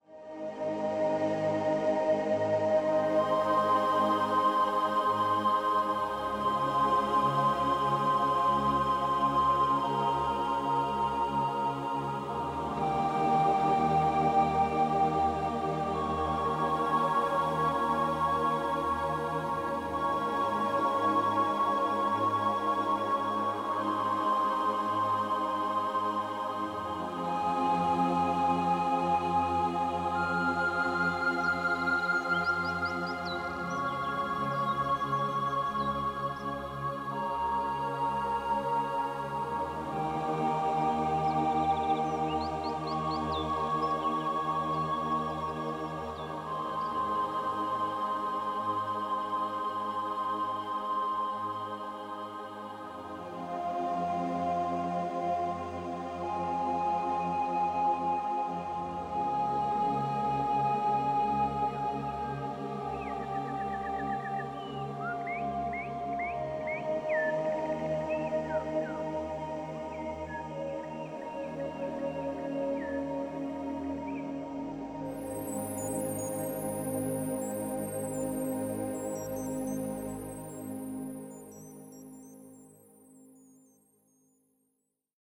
heel ontspannend, met nogal wat natuurgeluiden